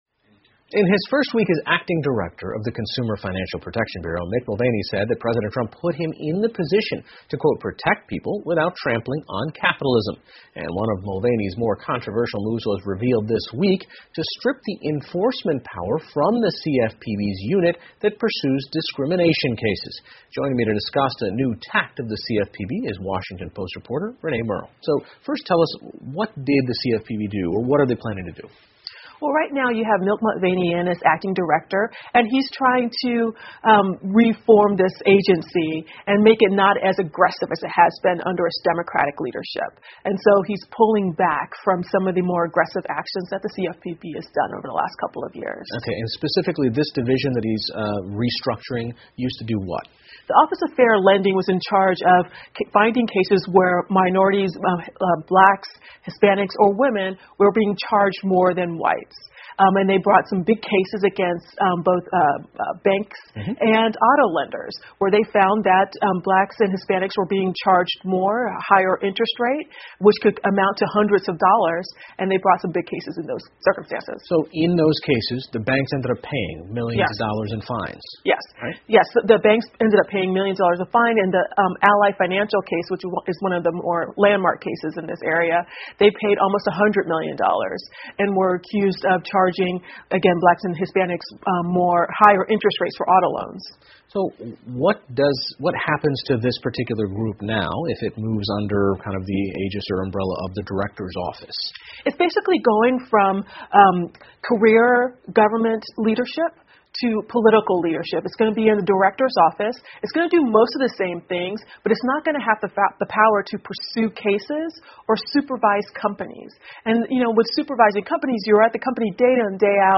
PBS高端访谈:消费者金融保护局的执法变化将如何影响消费者 听力文件下载—在线英语听力室